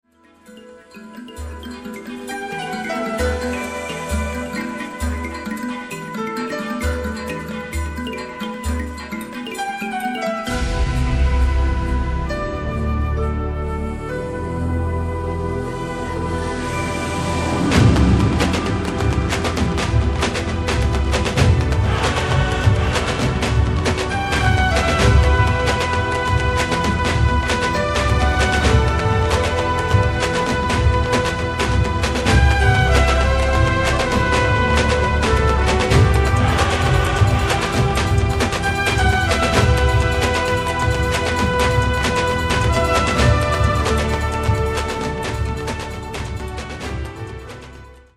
world music